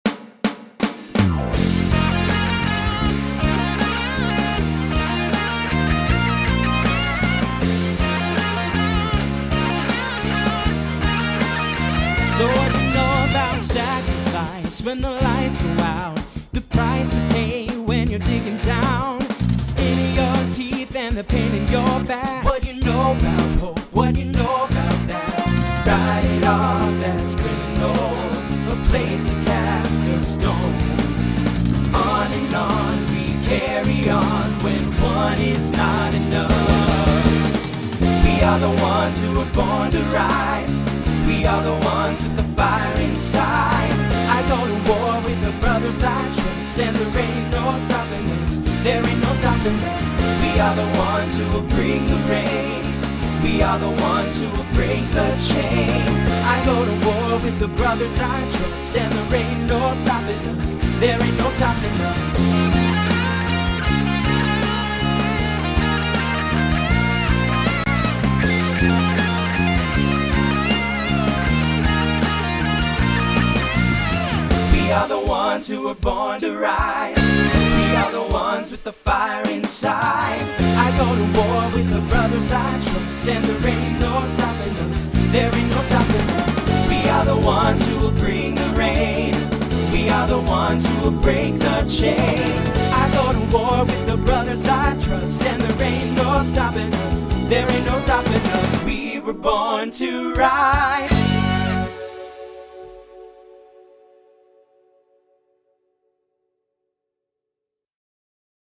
New SATB voicing for 2022.